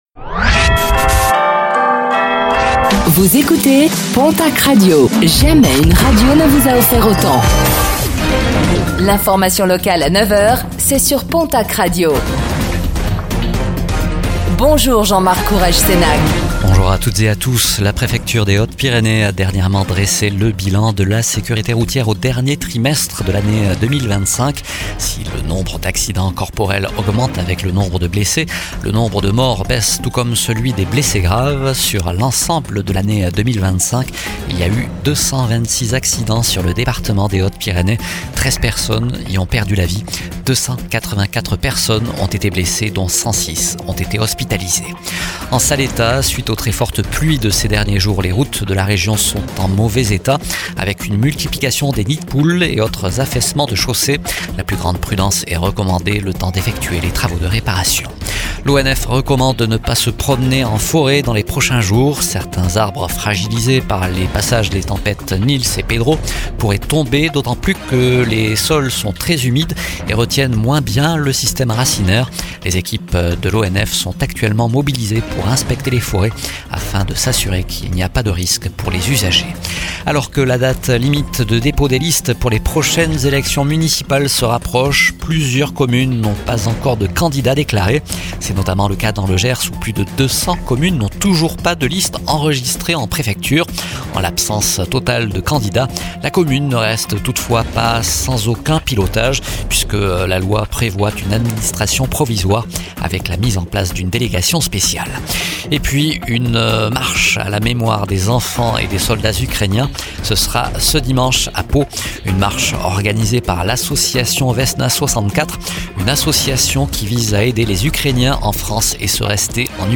Infos | Vendredi 20 février 2026